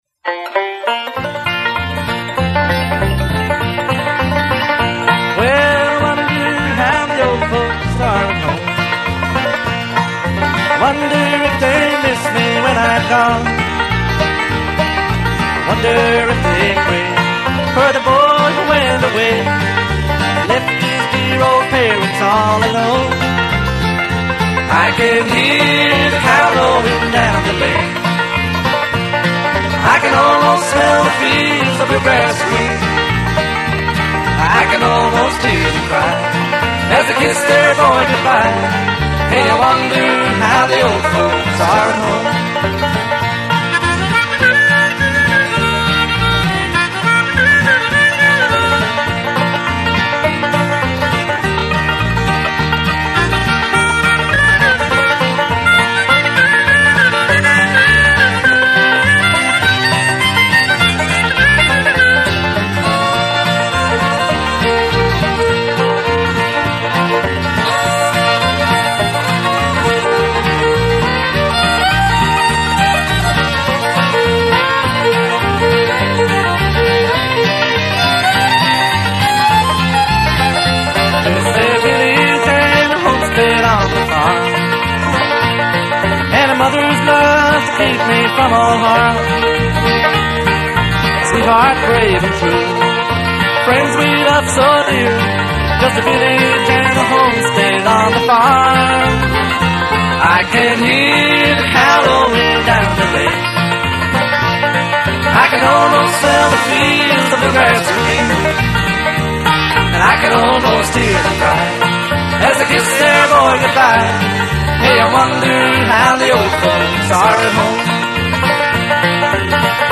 American Bluegrass cross-over into country folk.